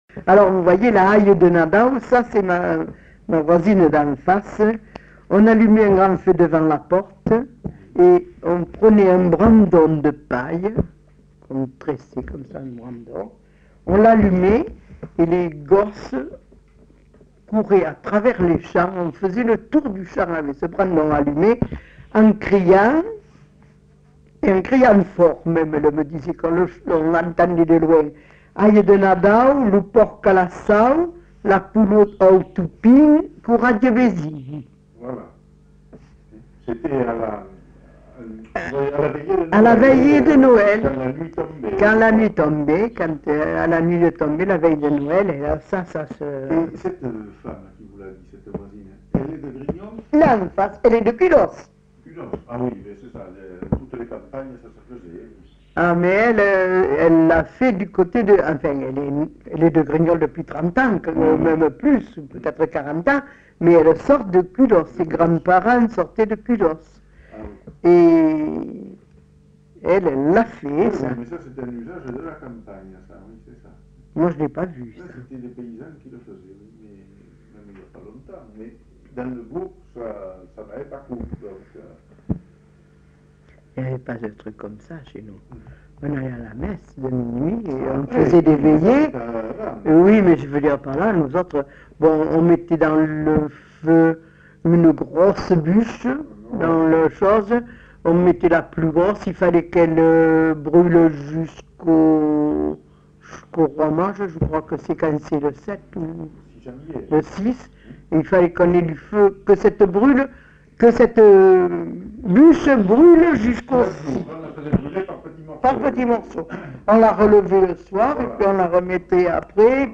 Lieu : Grignols
Genre : témoignage thématique
Classification : formulette